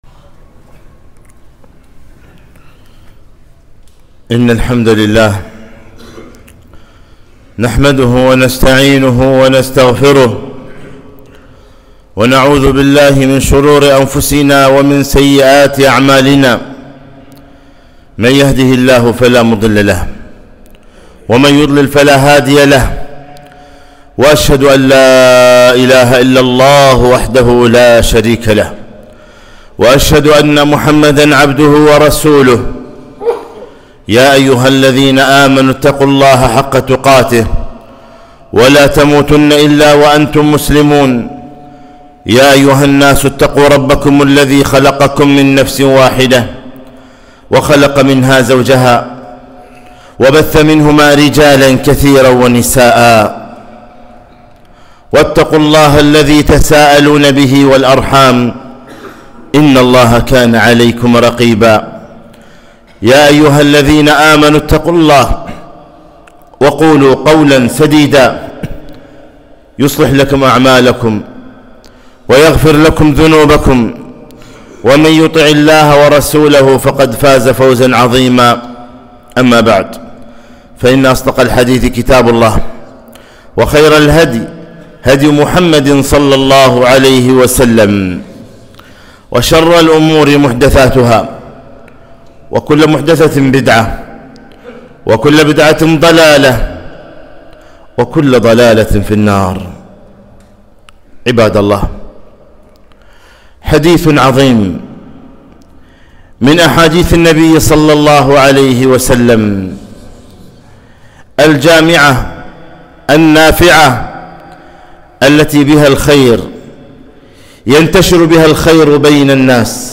خطبة - تدخلوا الجنة بسلام